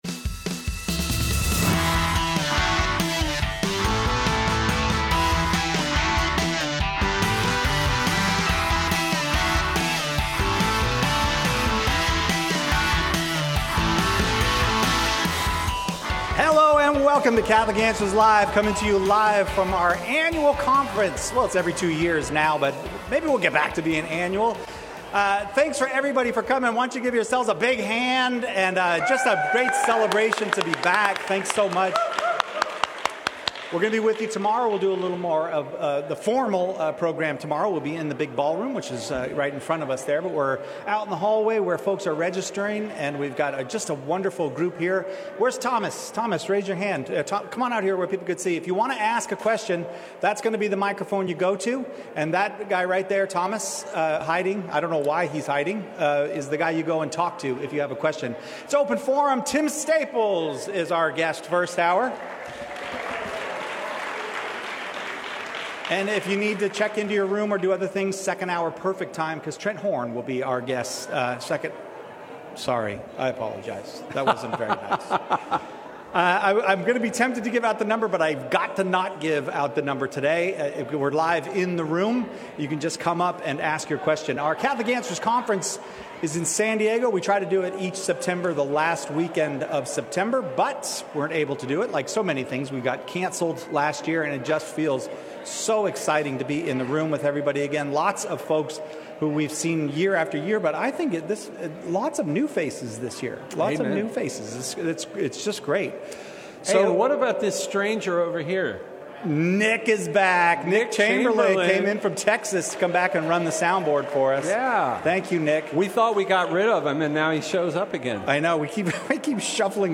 Live from the first day of the Catholic Answers Conference!